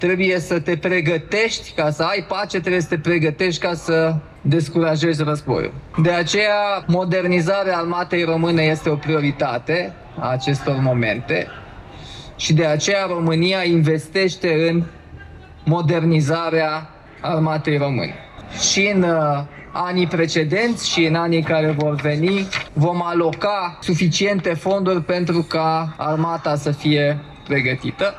Președintele Nicușor Dan a participat la Iași la evenimentele dedicate Zilei Armatei României.
Alocuțiunea sa a fost întâmpinată de o serie de huiduieli lansate de un grup de aproximativ 50 de persoane cu megafoane.